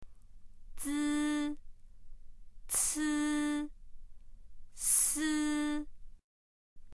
（　）の母音をつけた第一声の発音を聞いてみましょう。
zi-ci-si.mp3